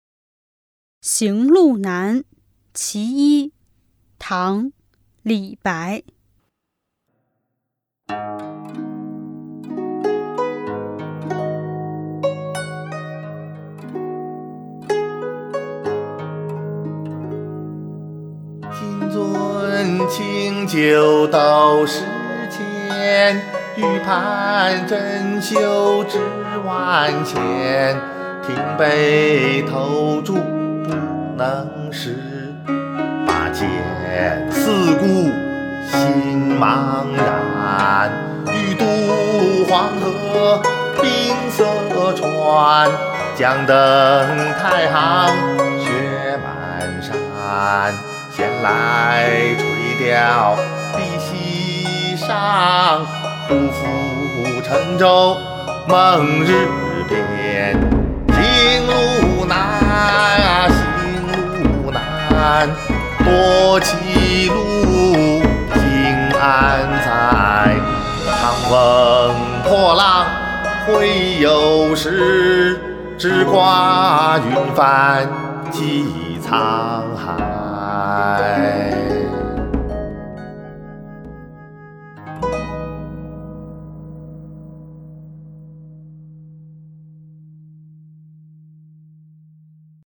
［唐］李白  行路难（其一）（吟咏）